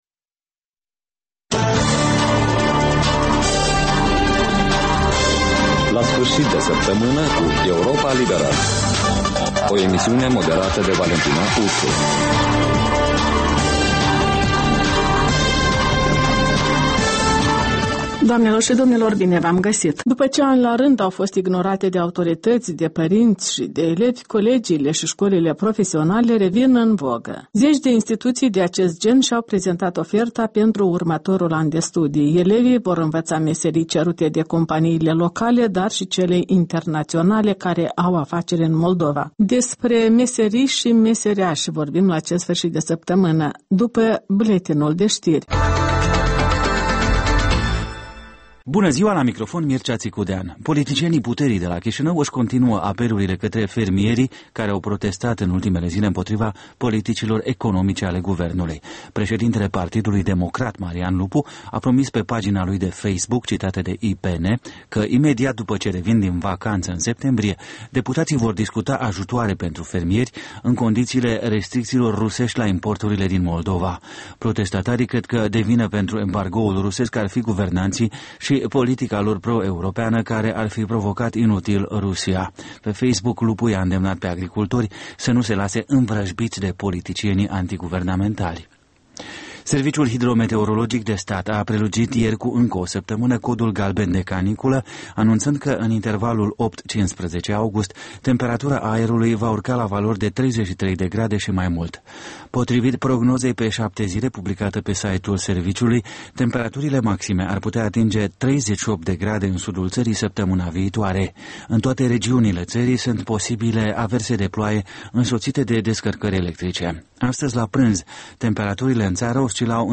In fiecare sîmbătă, un invitat al Europei Libere semneaza „Jurnalul săptămînal”.